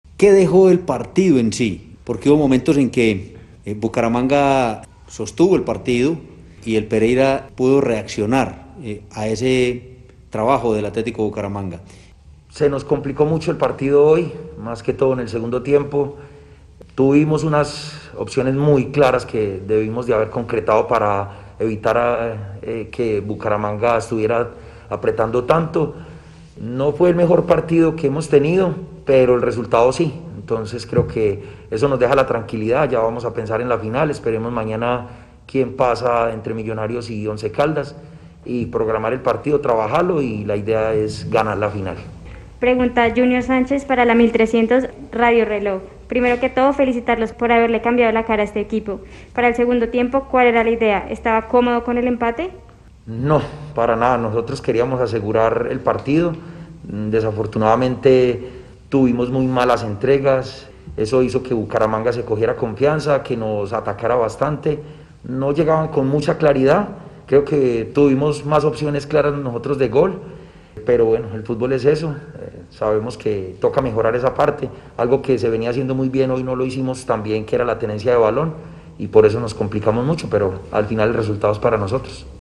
en rueda de prensa vía Win Sports +